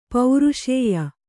♪ pauruṣēya